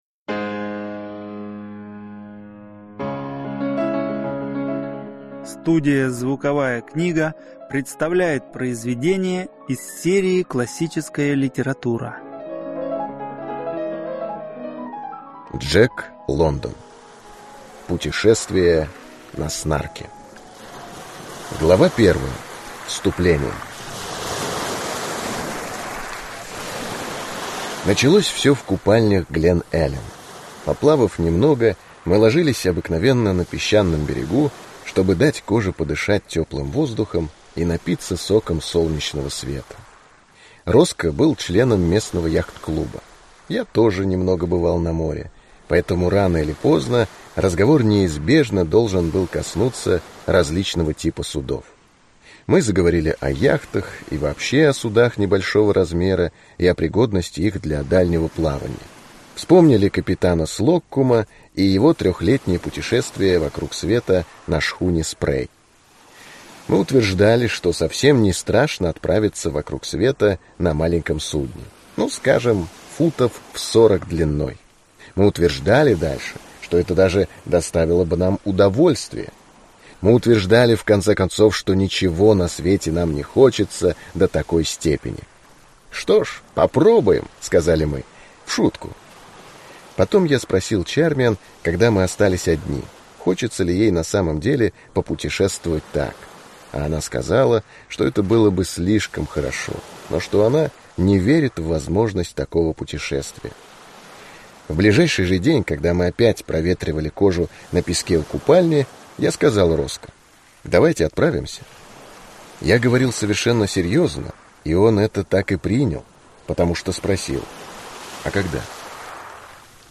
Аудиокнига Путешествие на «Снарке» | Библиотека аудиокниг